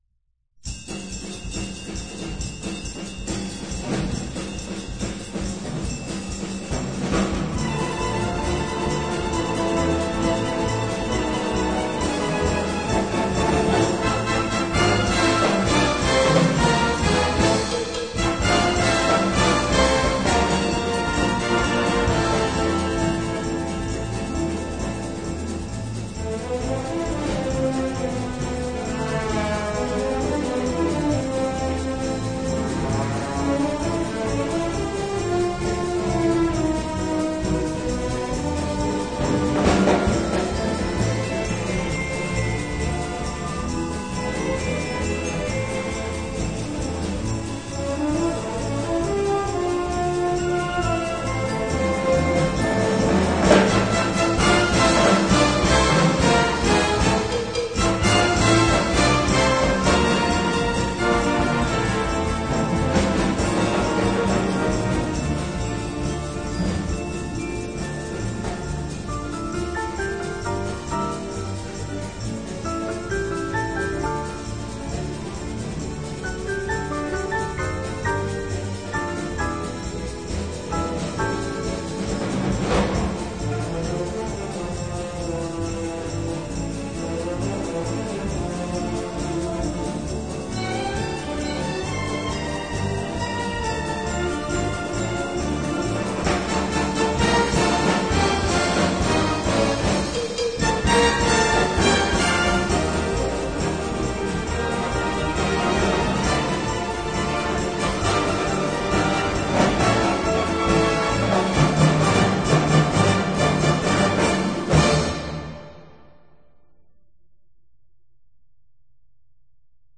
作品旋律优美，有缠绵感人的，亦有轻快活泼的一面。